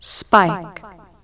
spike.wav